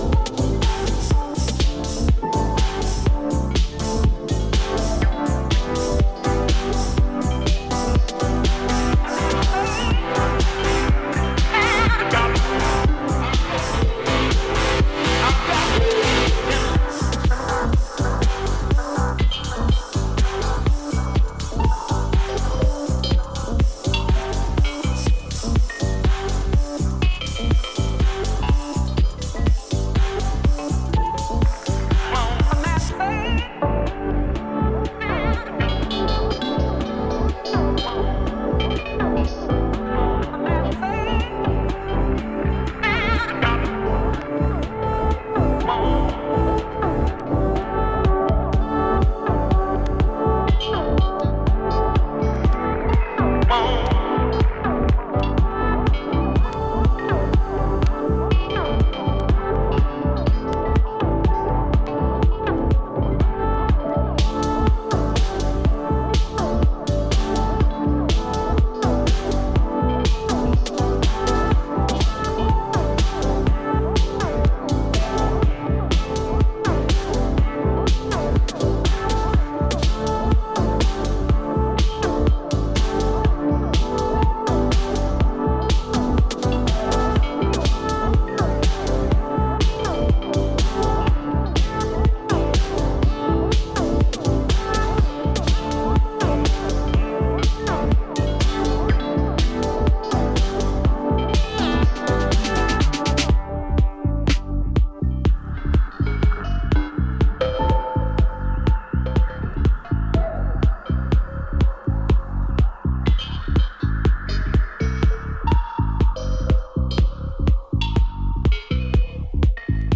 ノスタルジックなコードが印象的なミニマル・ハウス